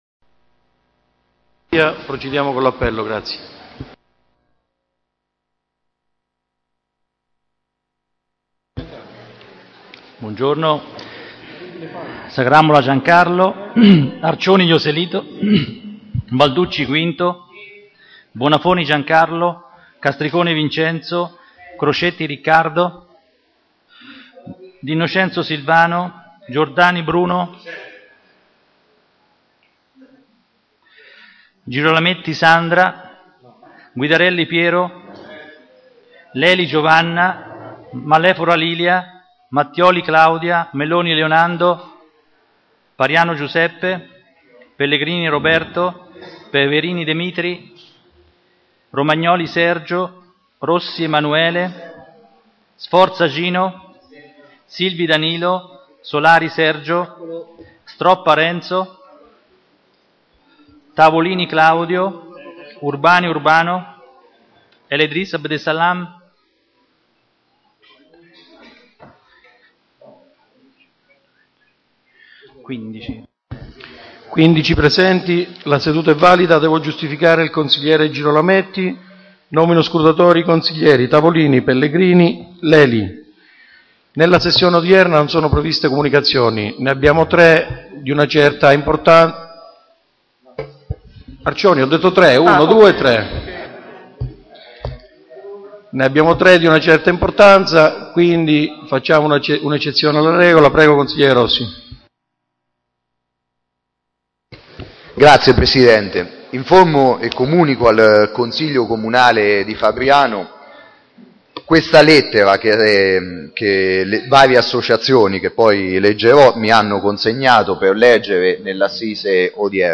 FILE AUDIO DELLA SEDUTA